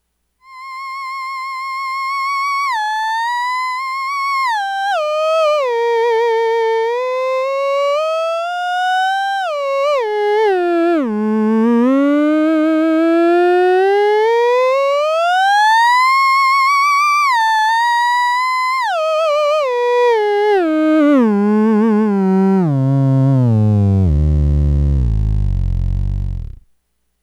Removed 555, this can add nice grittiness to the sound, not used in sample below.
Sine wave signal C2 is almost clean, scope shows a bit of noise on peaks
Sound Byte 3   If you get rid of the third harmonic sound becomes more human.